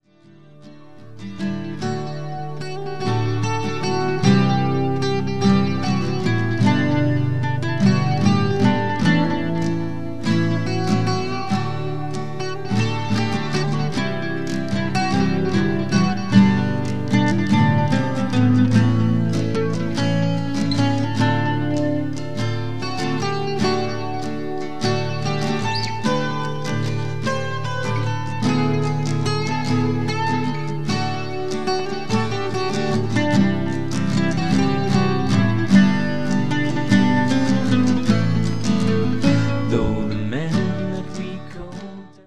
Ibanez PF60CENT1202 'semi' acoustic.
Bodrhan.
Quickshot mic (yes, really!)